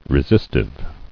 [re·sis·tive]